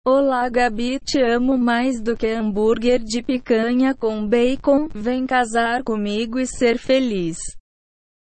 translate_tts_OtqSQrk.mp3